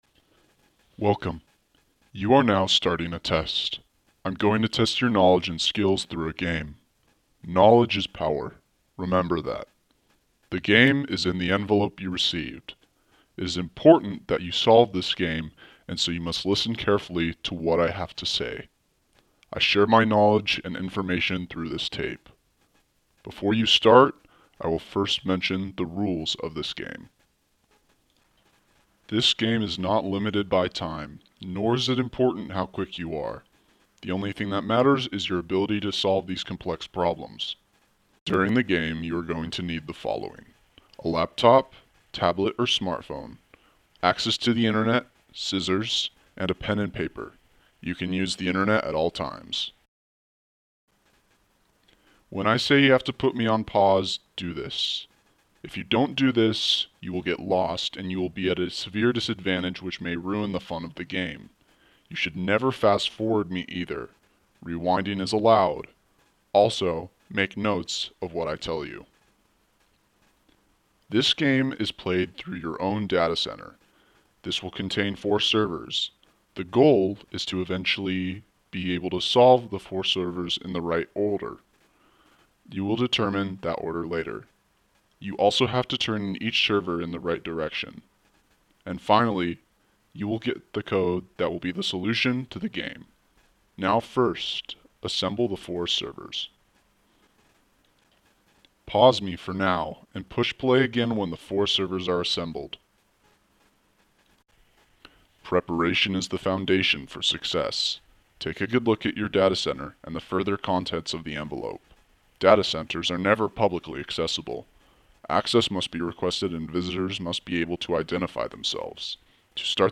A package with unknown content and a mysterious voice.